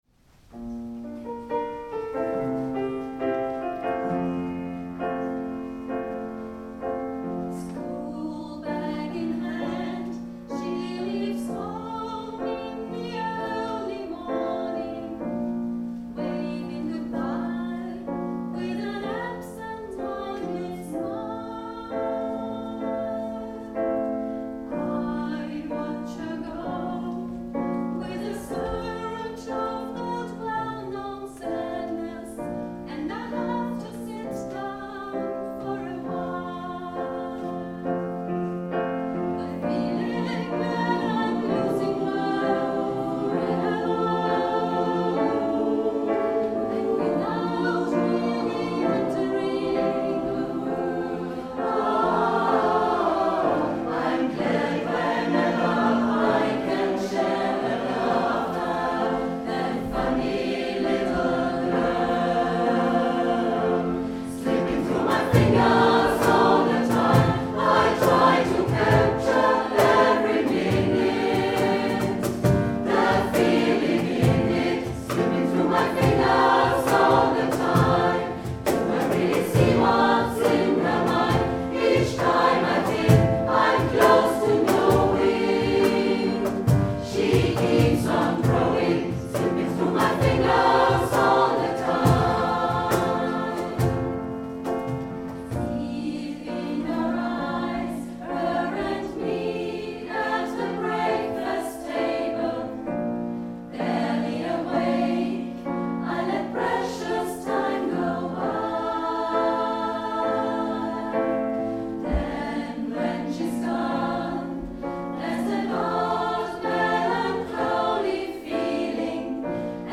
24.06.2017 - Konzert - SOMMERCOCKTAIL